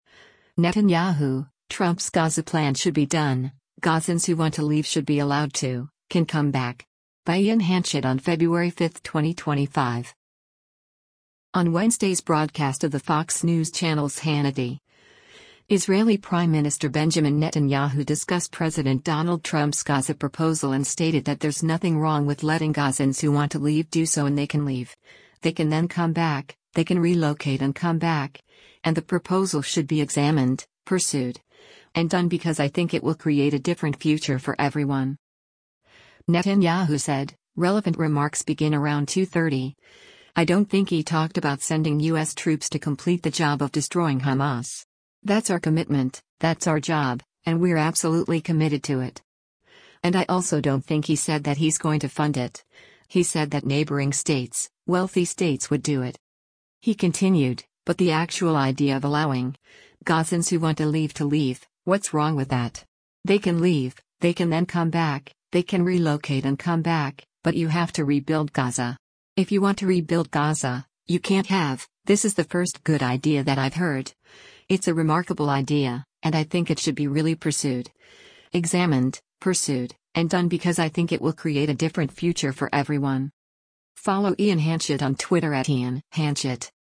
On Wednesday’s broadcast of the Fox News Channel’s “Hannity,” Israeli Prime Minister Benjamin Netanyahu discussed President Donald Trump’s Gaza proposal and stated that there’s nothing wrong with letting Gazans who want to leave do so and “They can leave, they can then come back, they can relocate and come back,” and the proposal should be “examined, pursued, and done because I think it will create a different future for everyone.”